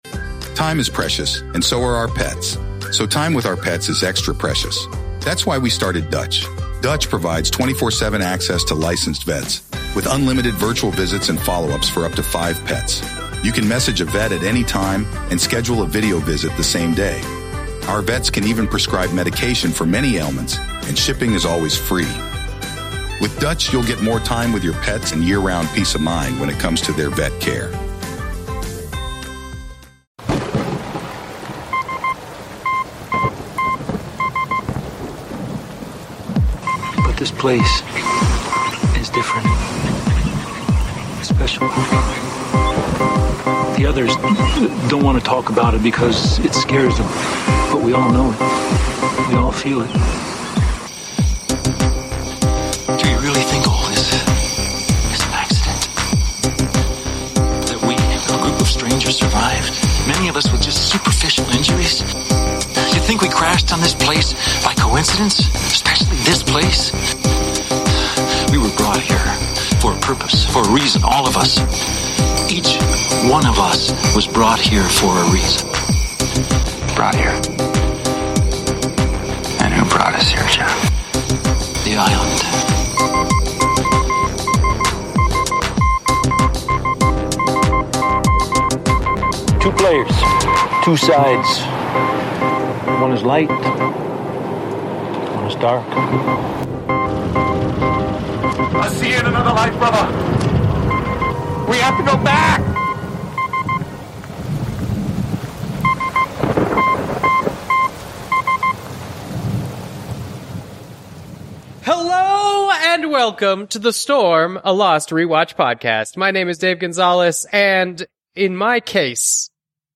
The Interview (No Spoilers) - 53 minutes, 28 seconds THE STORM (SPOILERS!)